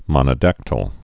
(mŏnə-dăktəl) Zoology